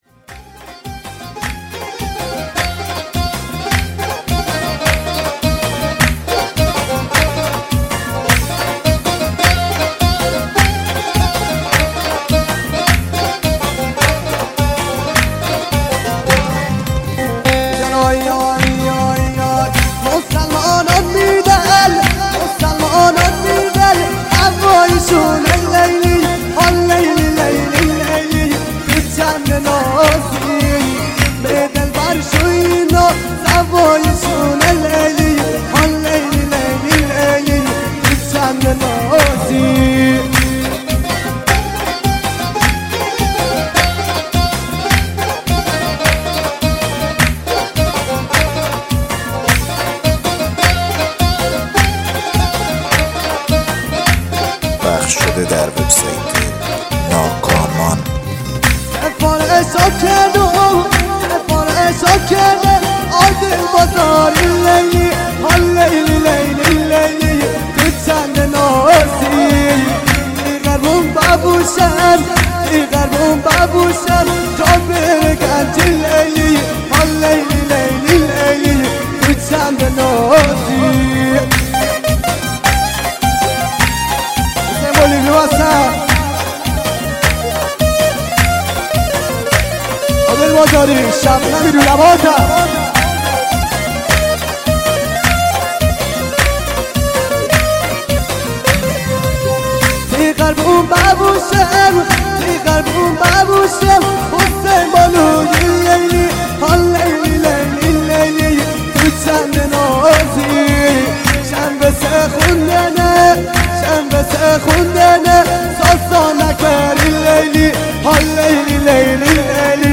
ورژن مازندرانی شمالی